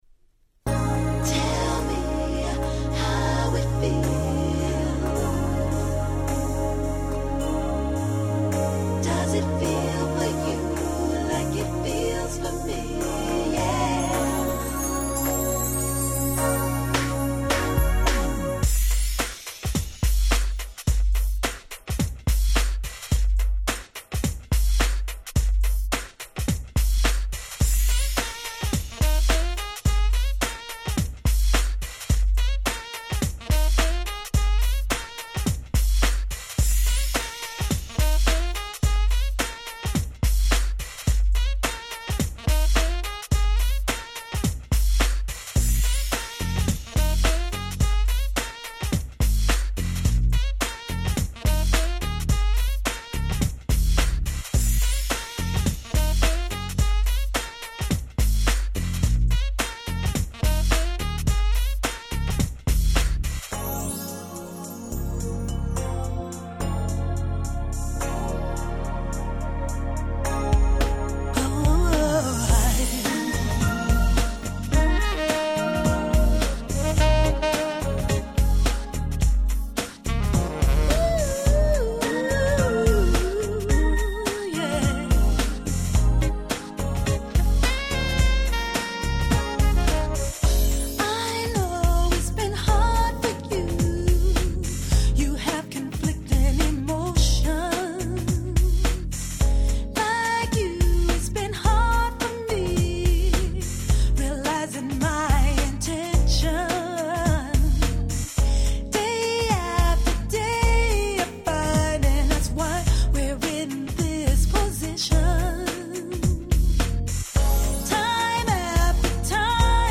UK Original Press.